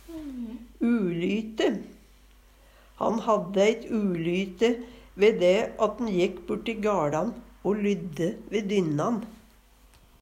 ulyte - Numedalsmål (en-US)